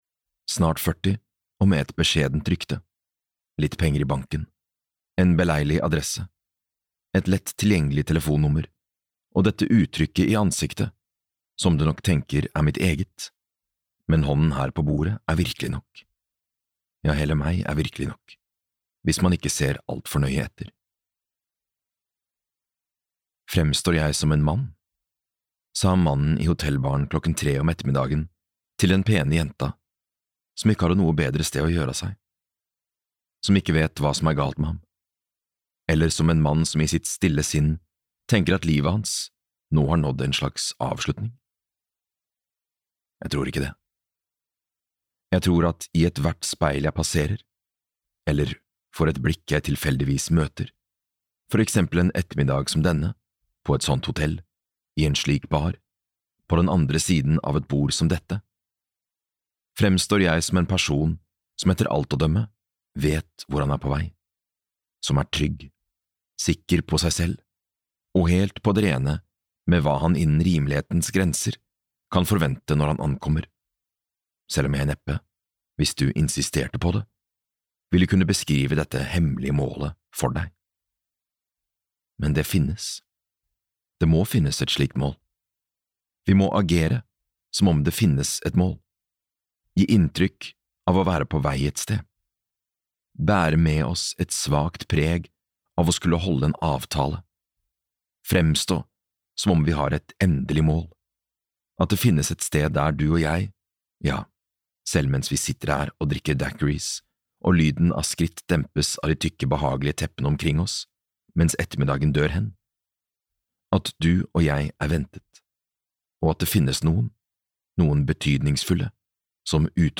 Forelsket (lydbok) av Alfred Hayes